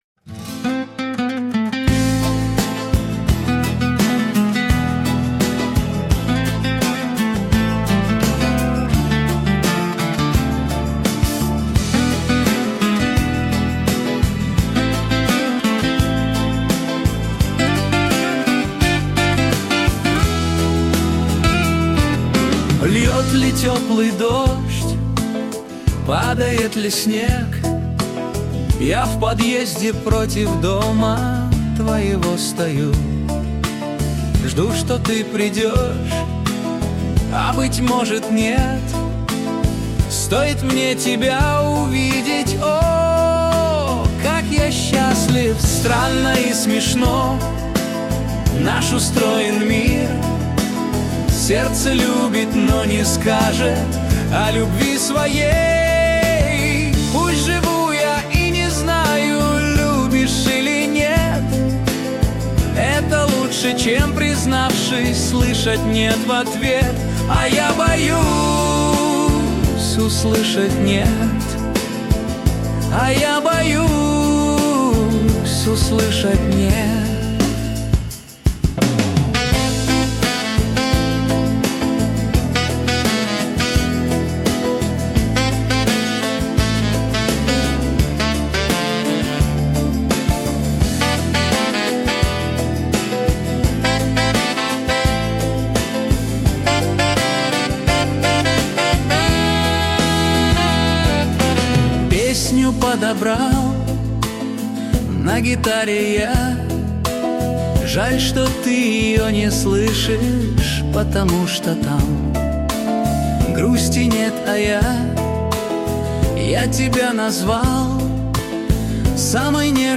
Кавер версия